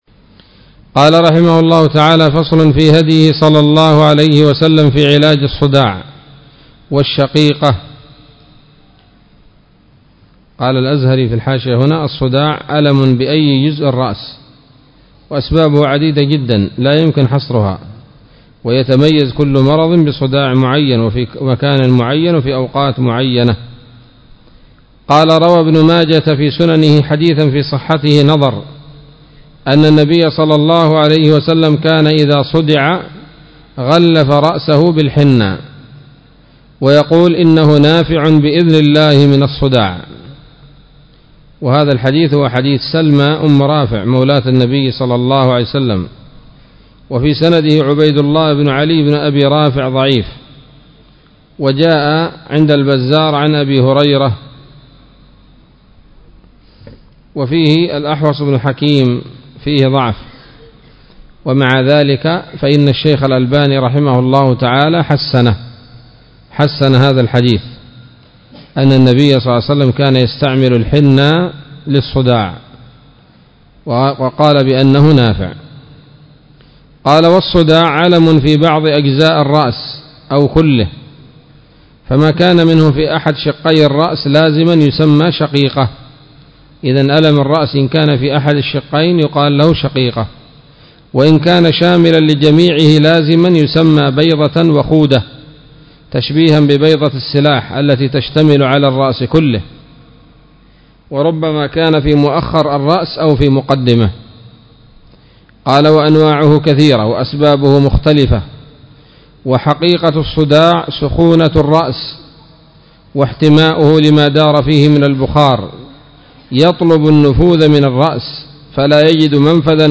الدرس الثالث والعشرون من كتاب الطب النبوي لابن القيم